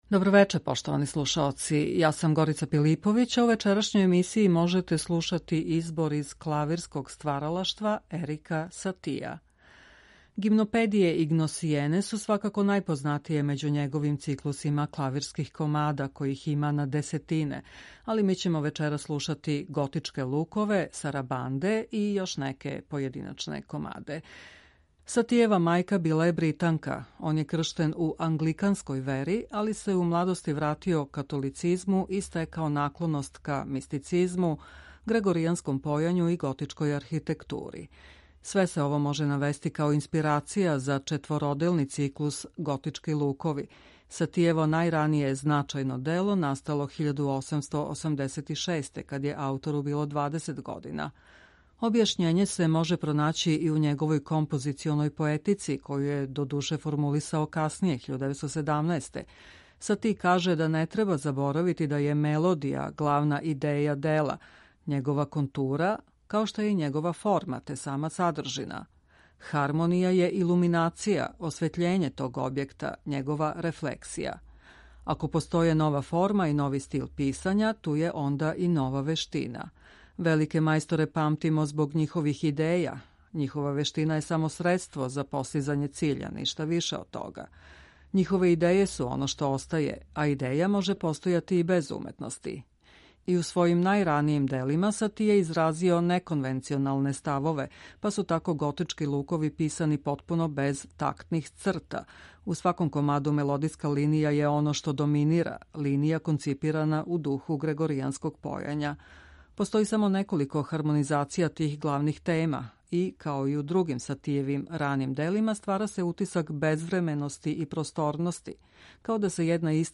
избор из клавирског стваралаштва